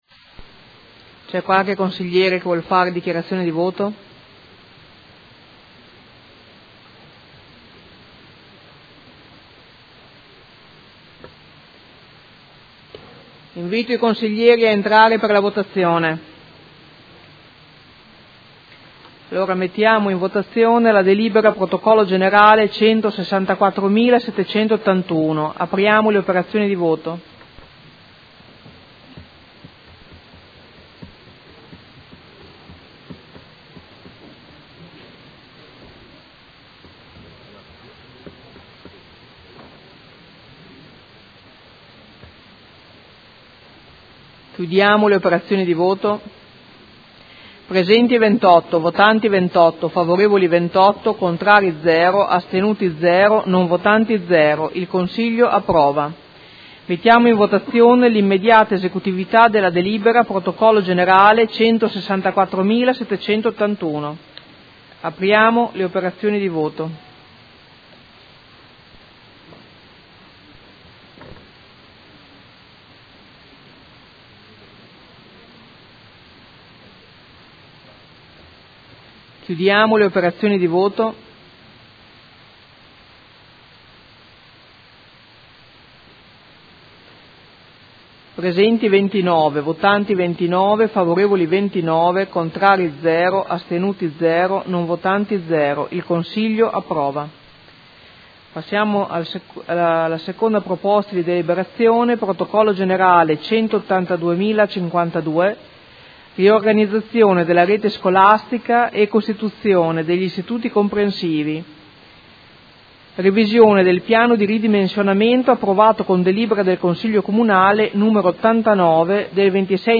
Seduta del 14/01/2016.